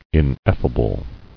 [in·ef·fa·ble]